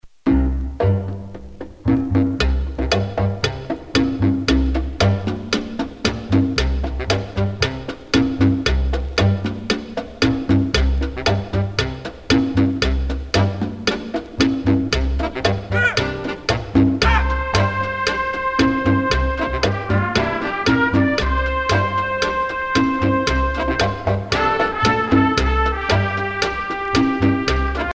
danse : cha cha cha
Pièce musicale éditée